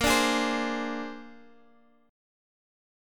Bb9 Chord
Listen to Bb9 strummed